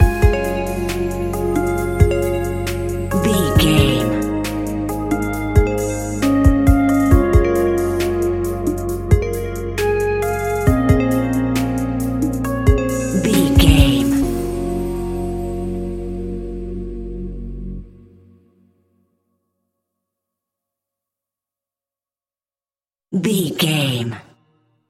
Aeolian/Minor
B♭
Slow
laid back
hip hop drums
hip hop synths
piano
hip hop pads